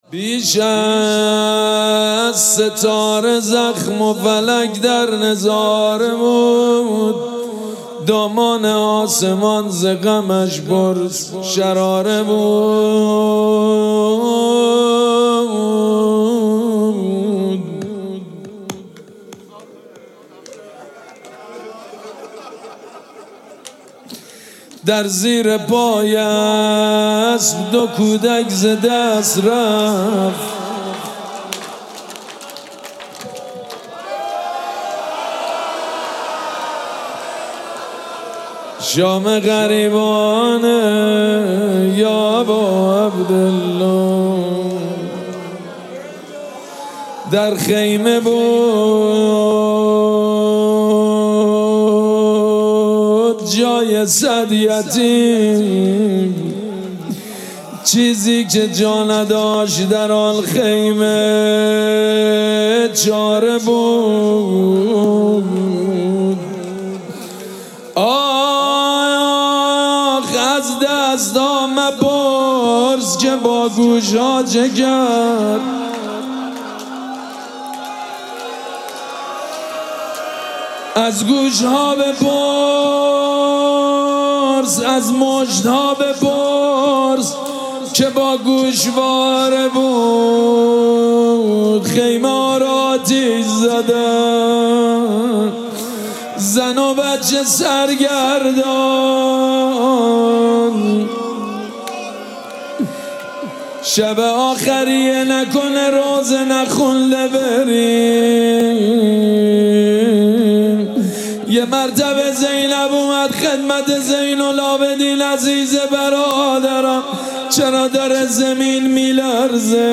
مراسم عزاداری شام غریبان محرم الحرام ۱۴۴۷
روضه
مداح